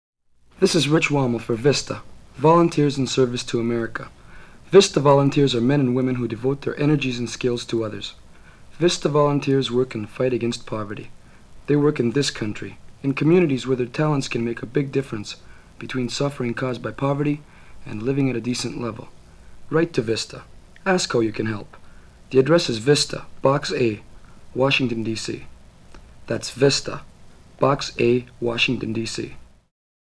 (Public Service Announcements for